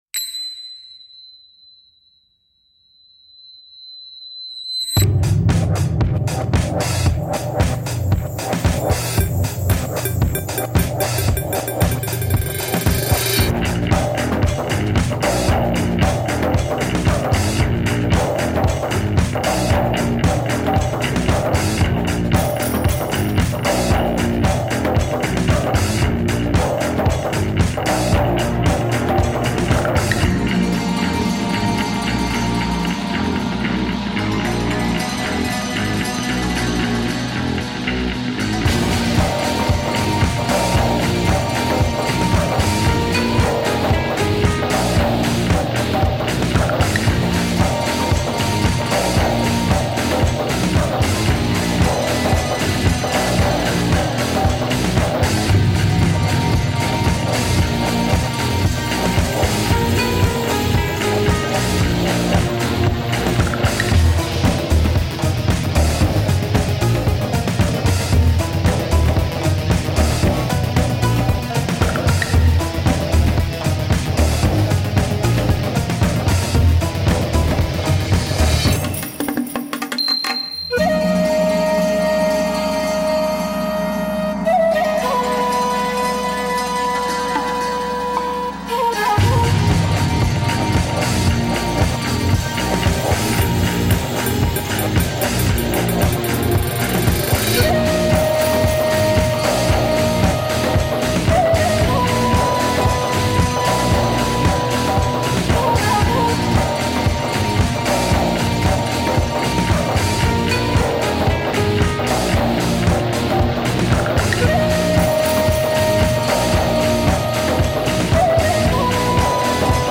Passionate eastern percussion.
Tagged as: World, Other, Arabic influenced